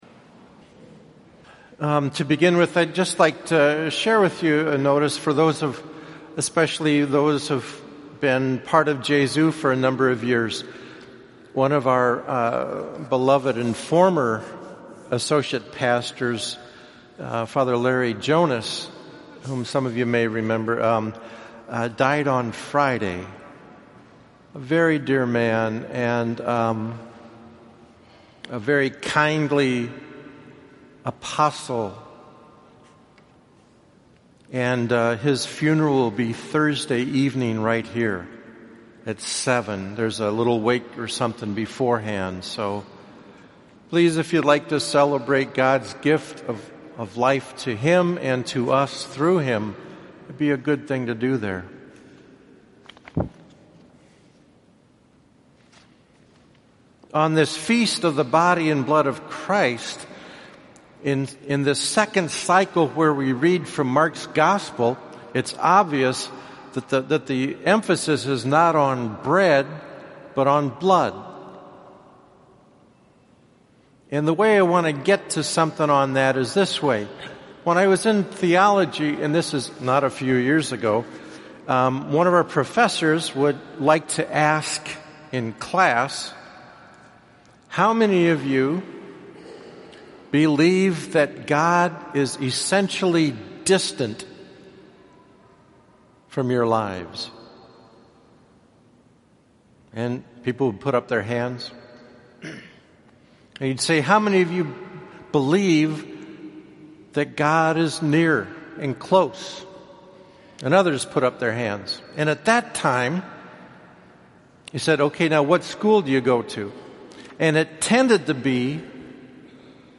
Solemnity of the Body and Blood of Christ 2015 (6:00 p.m. Mass)